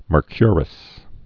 (mər-kyrəs, mûrkyər-əs)